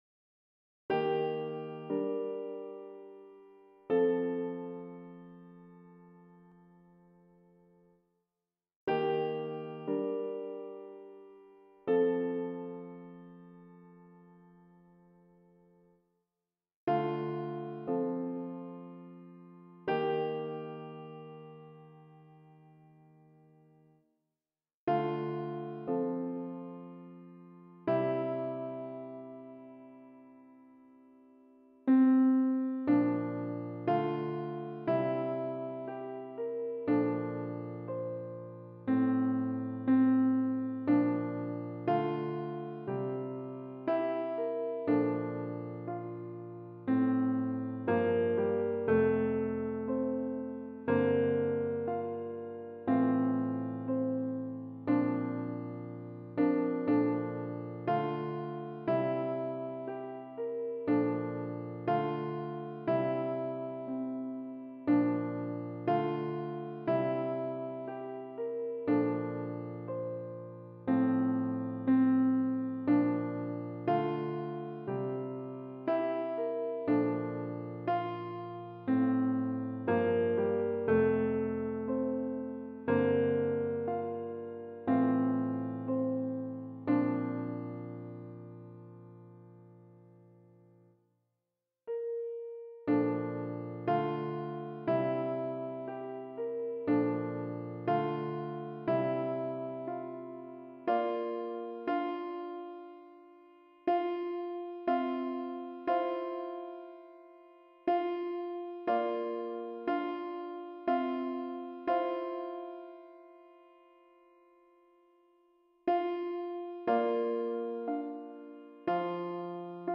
- Chant a capella à 4 voix mixtes SATB
Alto Piano